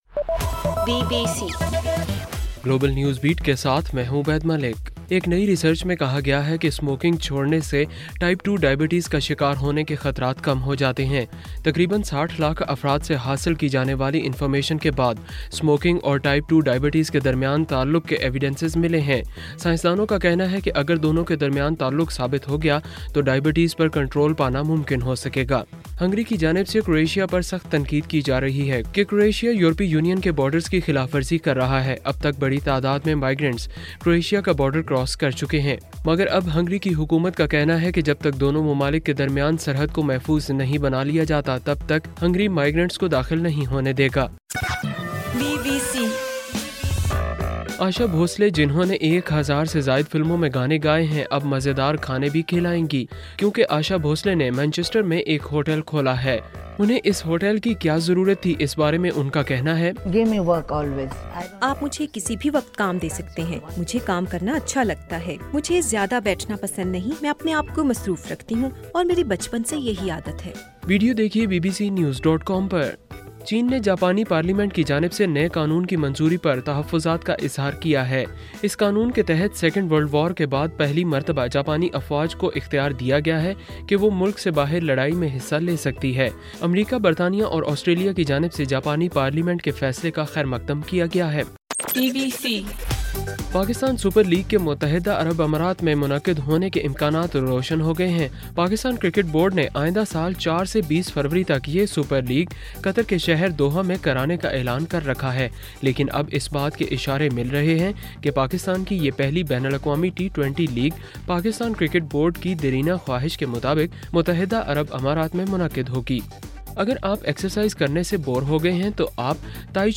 ستمبر 19: رات 11 بجے کا گلوبل نیوز بیٹ بُلیٹن